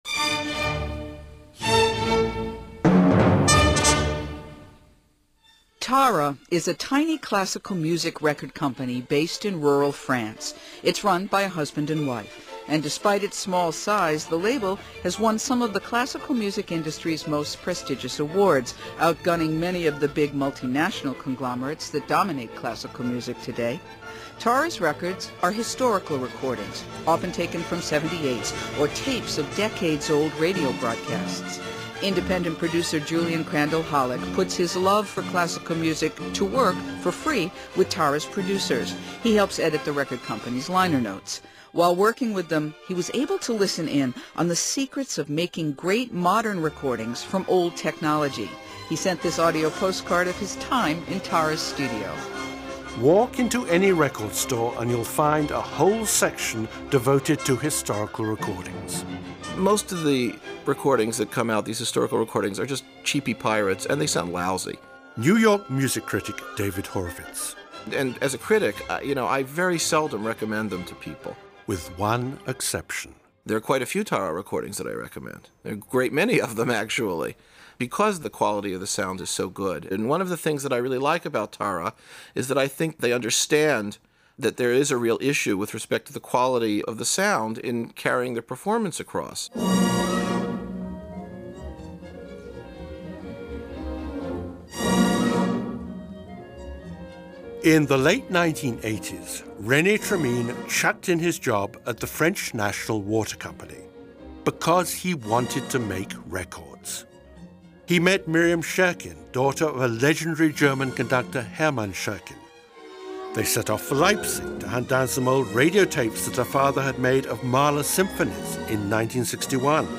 The recordings featured in the program are:
Beethoven 9: Furtwängler (1954) FURT 1003 or FURT 1054-1057
Mahler 3 (1961) TAH 497-498